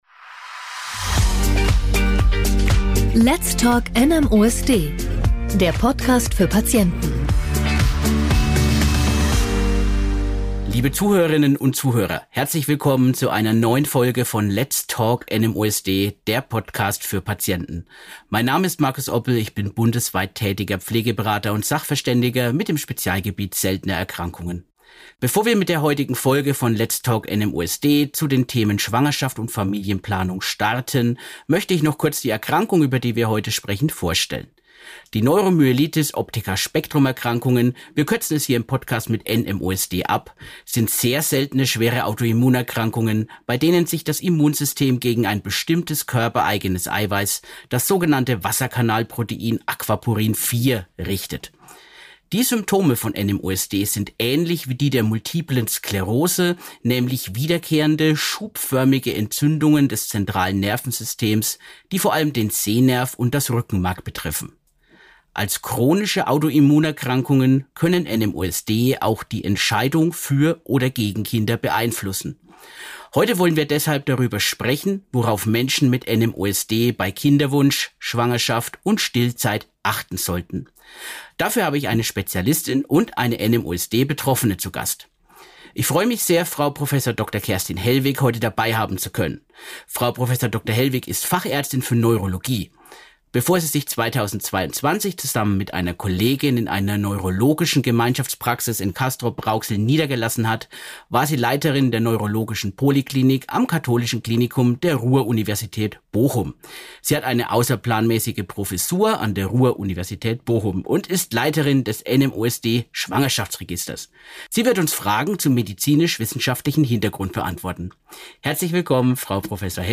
in einem spannenden Gespräch